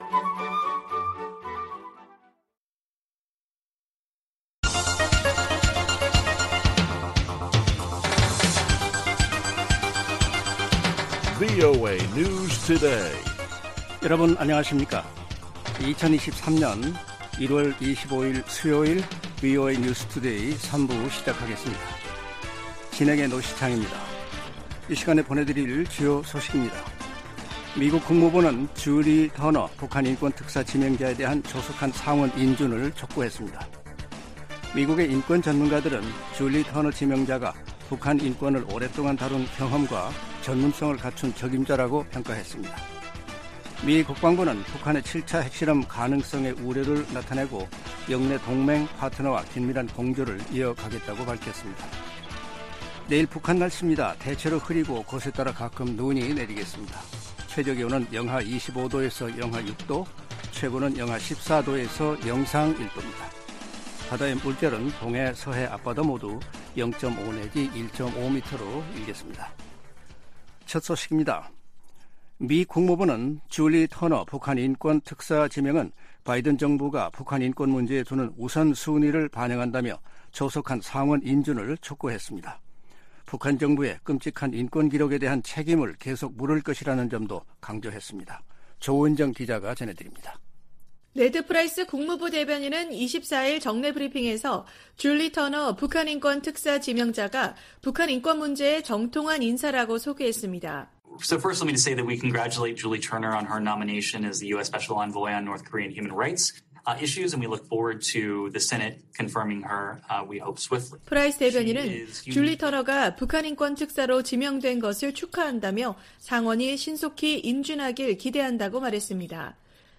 VOA 한국어 간판 뉴스 프로그램 '뉴스 투데이', 2023년 1월 25일 3부 방송입니다. 미 국무부는 줄리 터너 북한인권특사 지명자에 대한 조속한 상원 인준을 촉구했습니다. 미국의 인권 전문가들은 줄리 터너 지명자가 북한인권을 오랫동안 다룬 경험과 전문성을 갖춘 적임자라고평가했습니다.